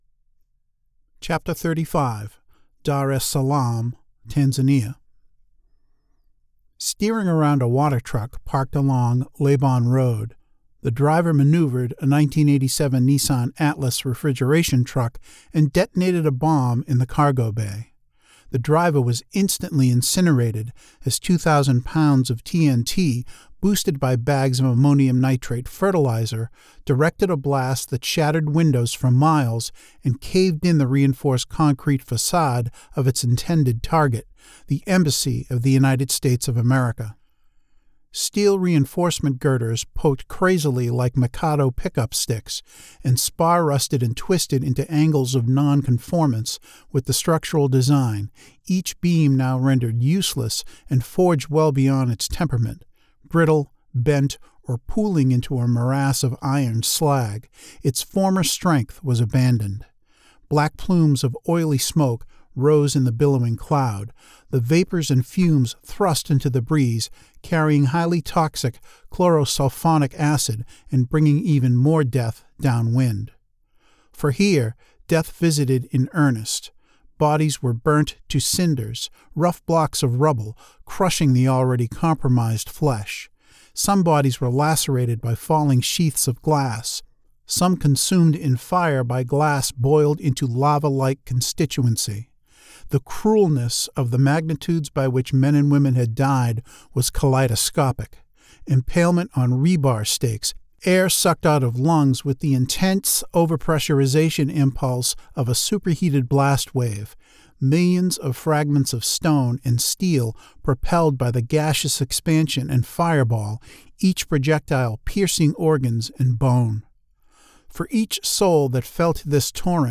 Here is a sample of the reading from 'Rosehips in June'.